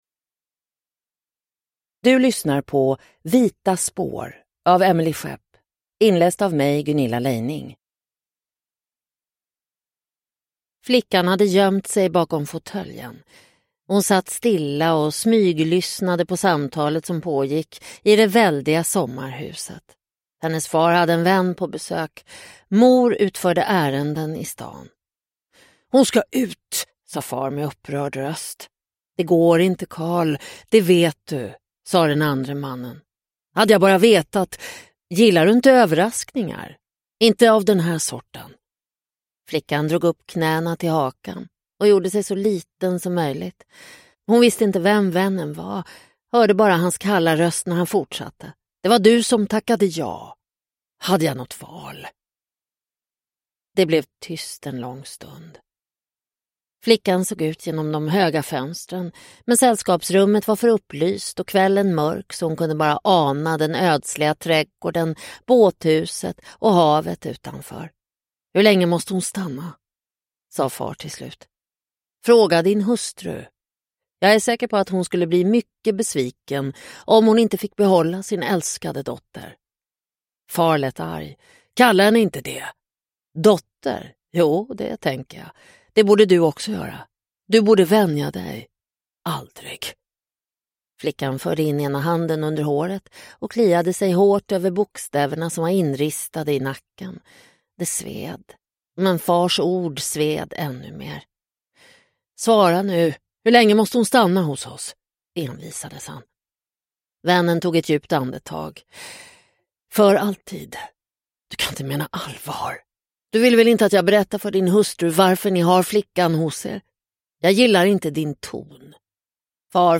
Vita spår – Ljudbok – Laddas ner